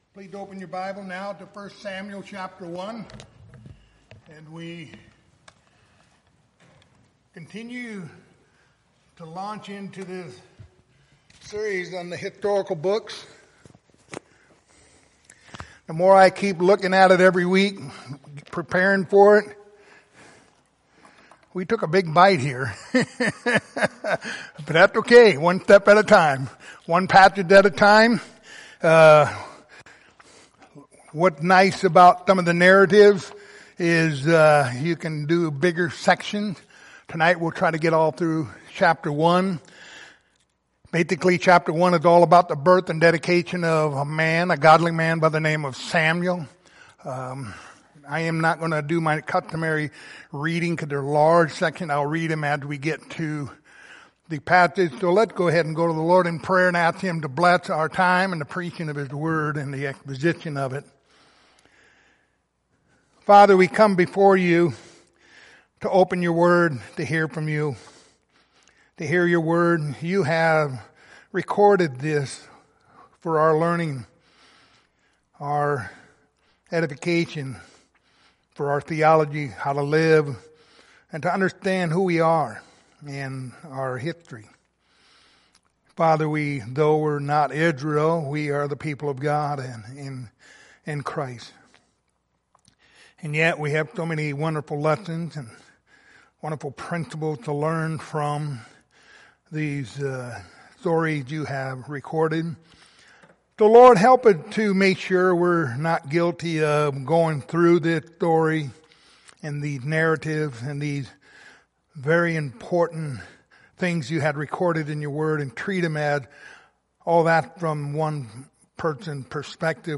Passage: 1 Samuel 1:1-28 Service Type: Wednesday Evening